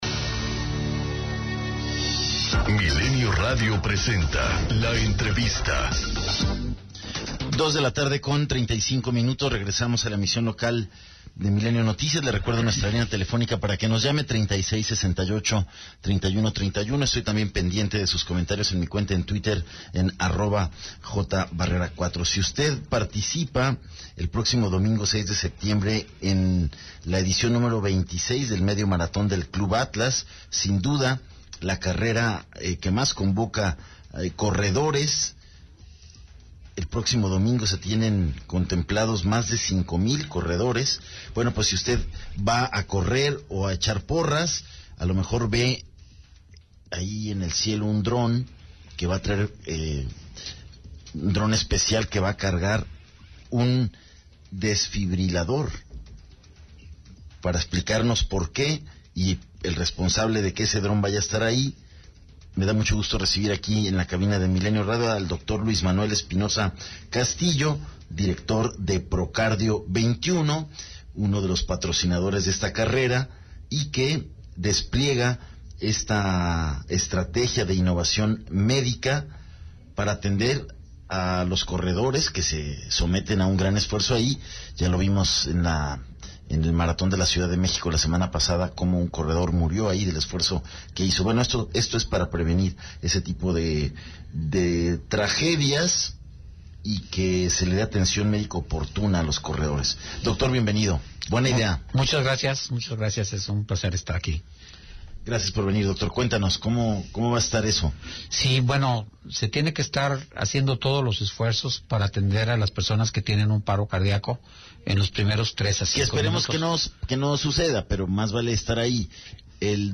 ENTREVISTA 040915